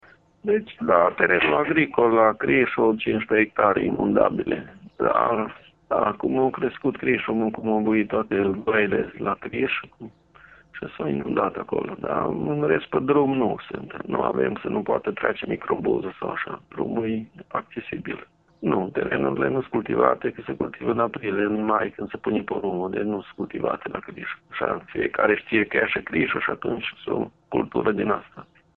Viceprimarul comunei Dieci, Florin Gheorghe Bogdan, spune că sunt inundate 15 hectare terenuri agricole, însă apa nu a ajuns pe drum.